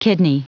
Prononciation du mot kidney en anglais (fichier audio)
Prononciation du mot : kidney